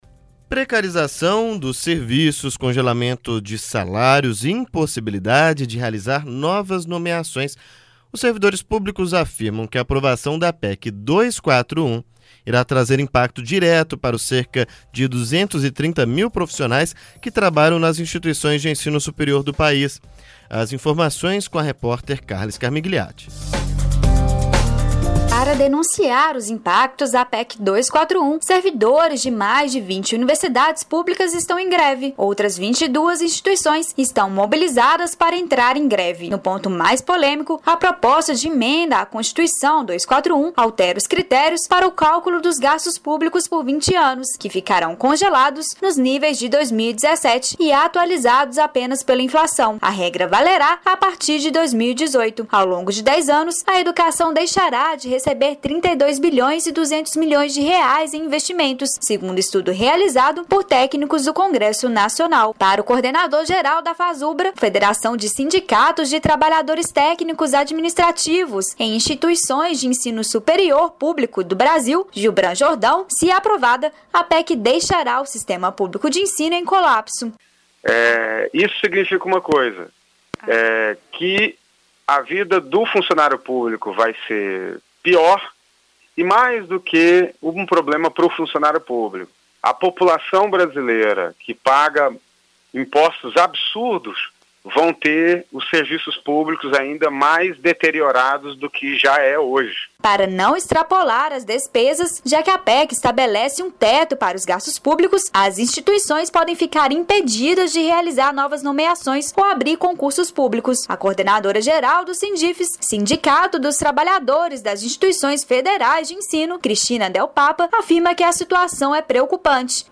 A categoria também protesta contra a disposição do governo federal de enviar ao Congresso projeto de reforça previdenciária, conforme esclarece reportagem produzida pela equipe da